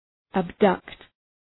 Προφορά
{æb’dʌkt}